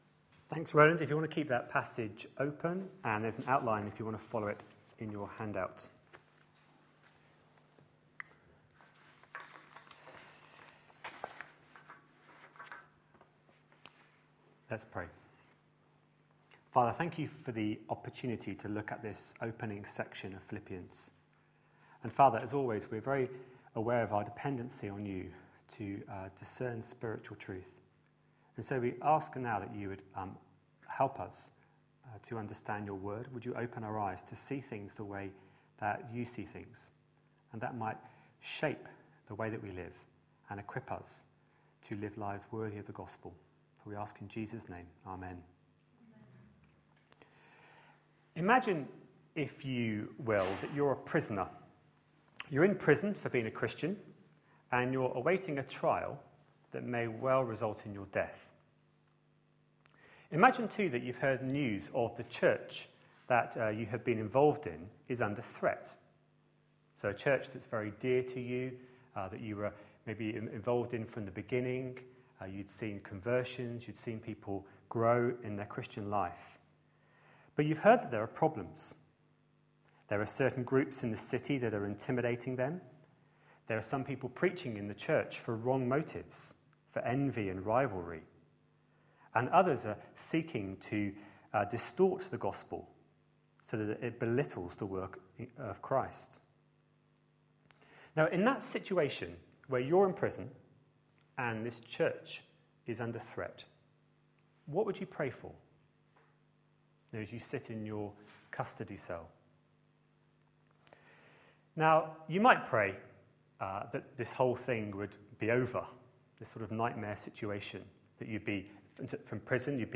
A sermon preached on 22nd July, 2012, as part of our Philippians series.